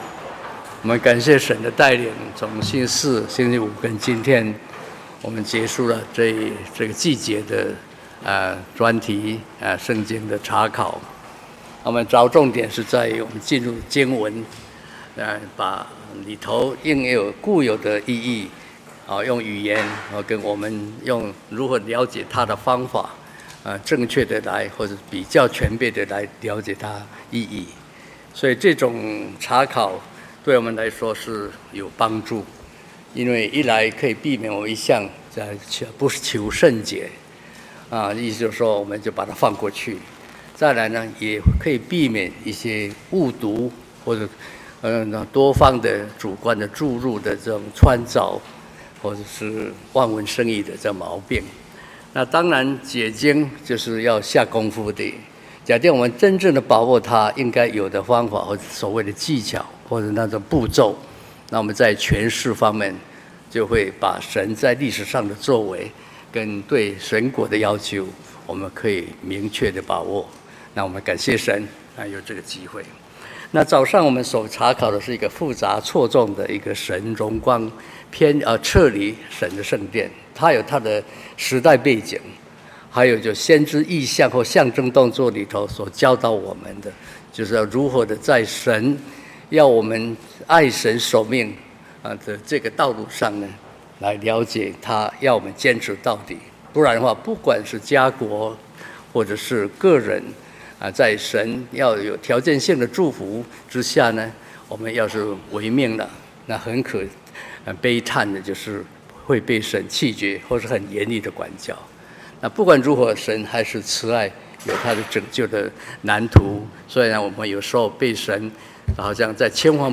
路得蒙恩的道理教訓-講道錄音